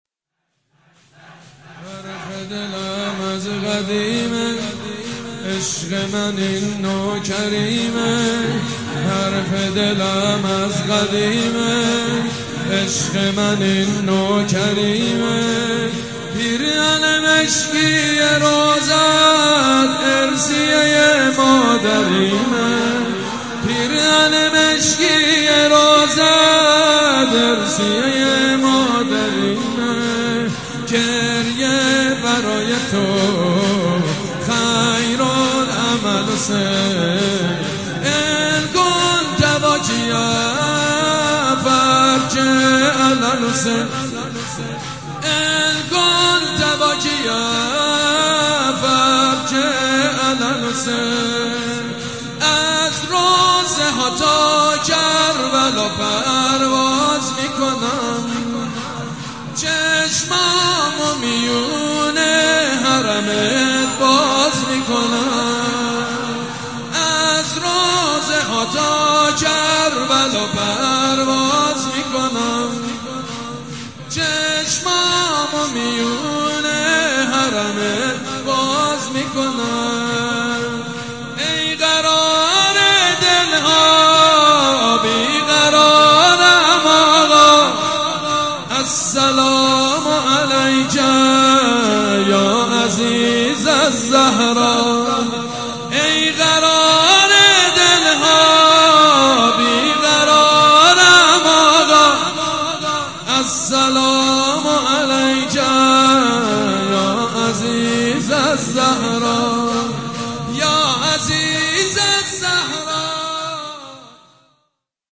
نوحه جديد
مداحی صوتی
شور